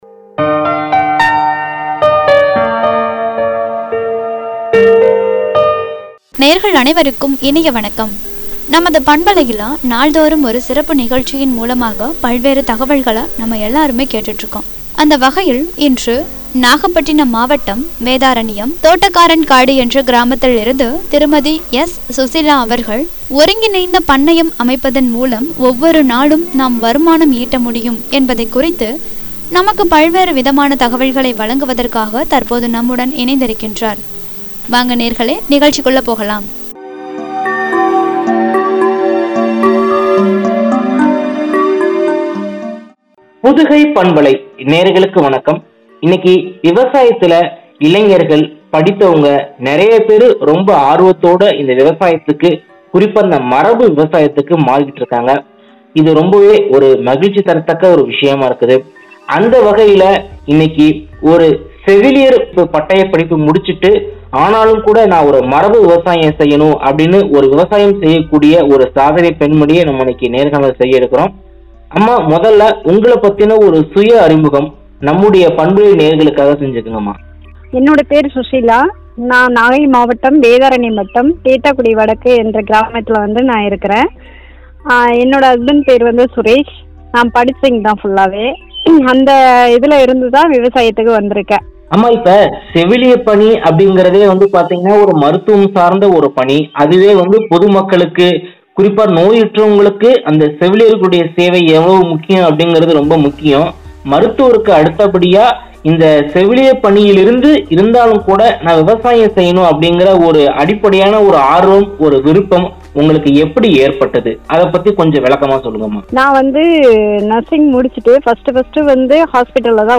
ஒவ்வொரு நாளும் வருமானம்” என்ற தலைப்பில் வழங்கிய உரையாடல்.